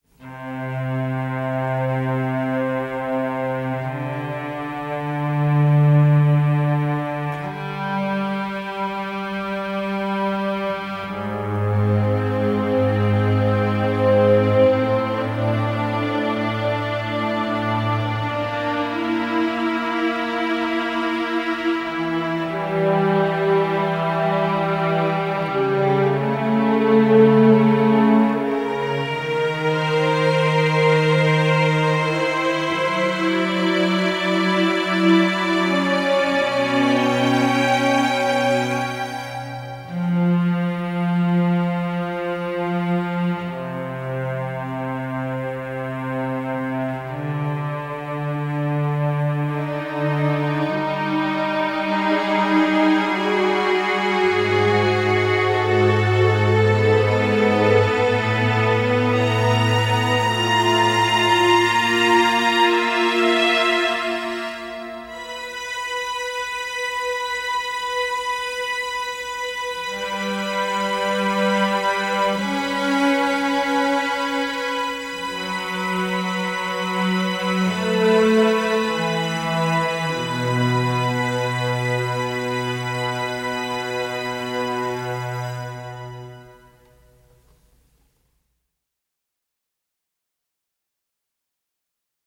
quatuor à cordes